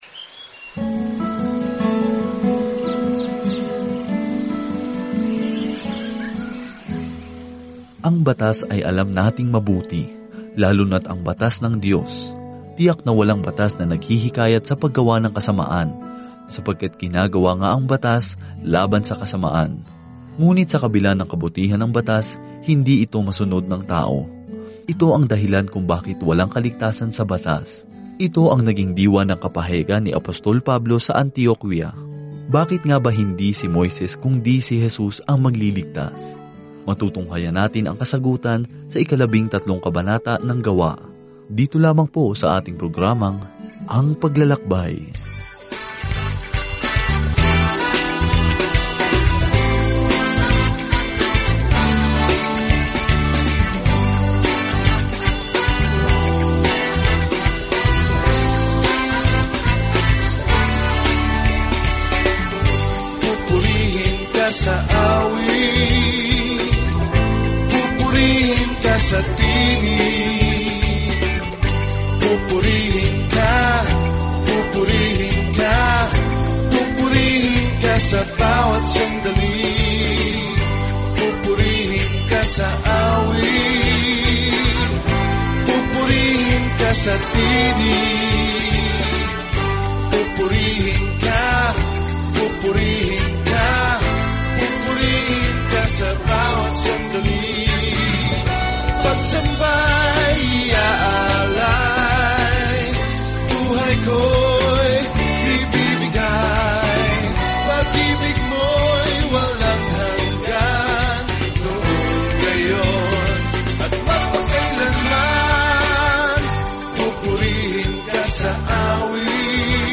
Araw-araw na paglalakbay sa Acts habang nakikinig ka sa audio study at nagbabasa ng mga piling talata mula sa salita ng Diyos.